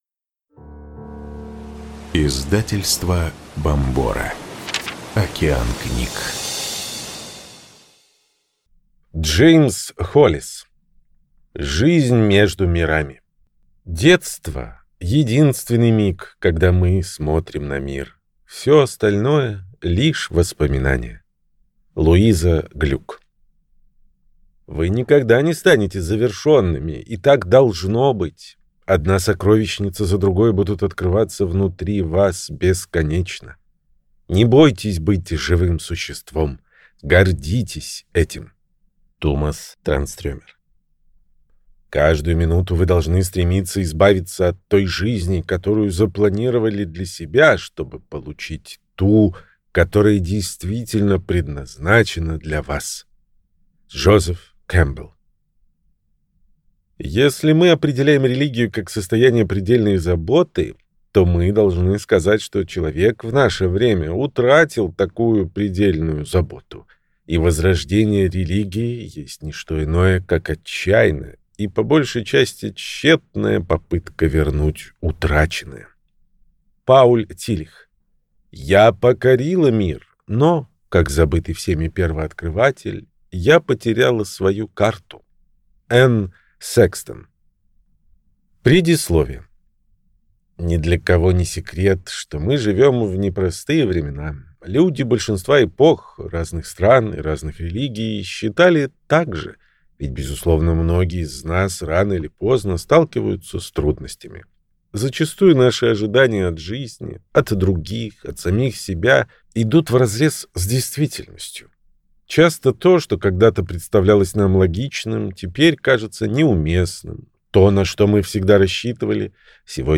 Аудиокнига Жизнь между мирами. Как найти ресурс в себе, когда все вокруг разваливается | Библиотека аудиокниг